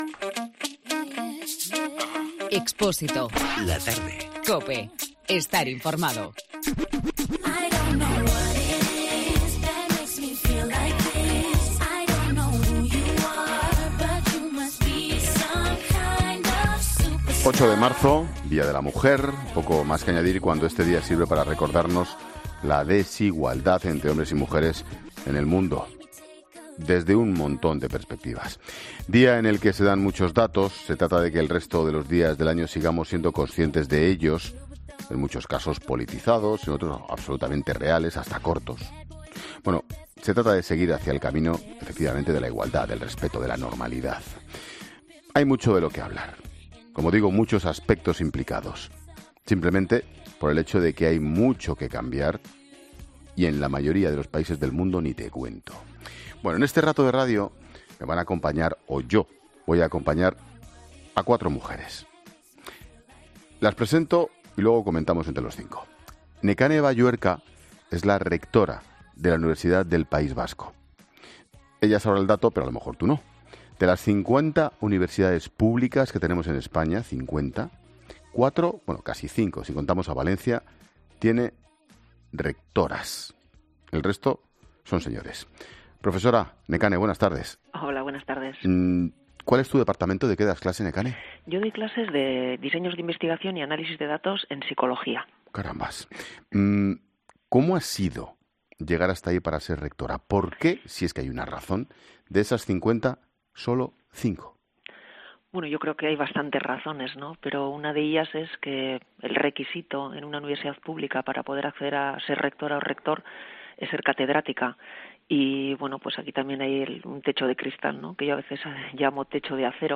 Hablamos en 'La Tarde' con cuatro mujeres pioneras en diferentes sectores y que ocupan cargos importantes